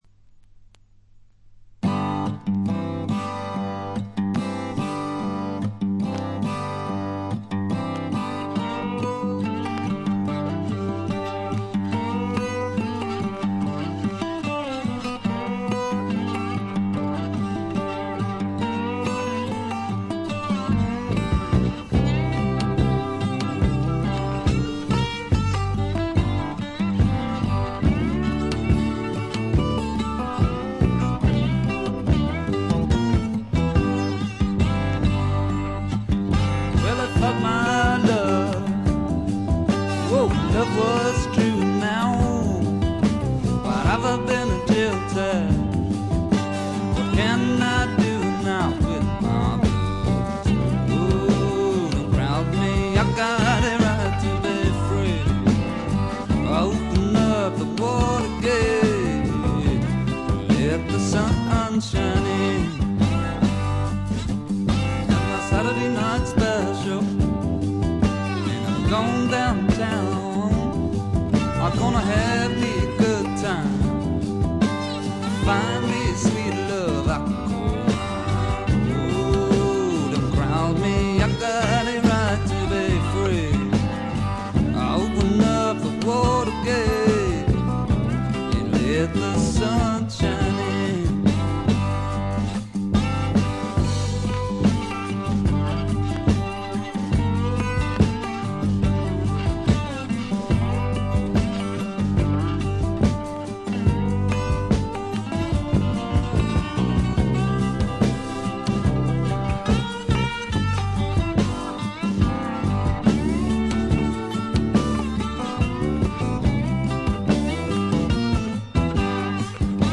チリプチがそこそこ。散発的なプツ音も少し。
試聴曲は現品からの取り込み音源です。